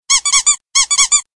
吱吱响的铃声
描述：吱吱作响的铃声
Tag: 滑稽 MMS 林格